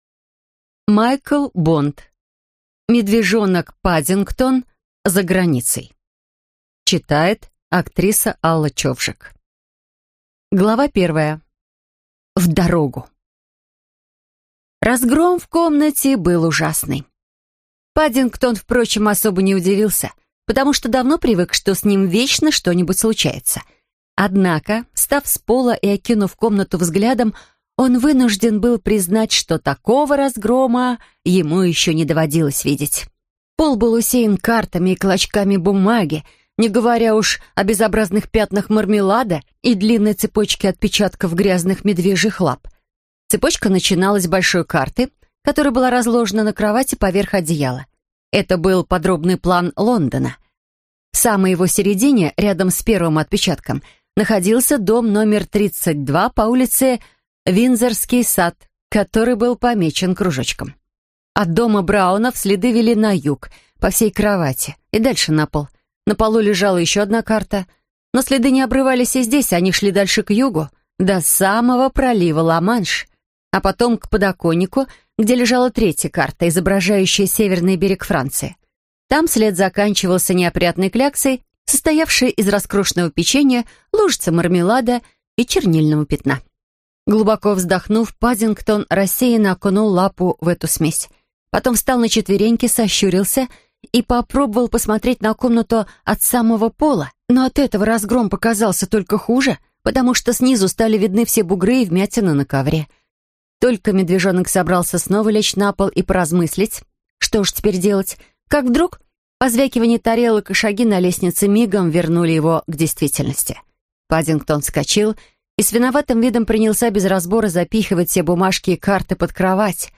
Аудиокнига Медвежонок Паддингтон за границей | Библиотека аудиокниг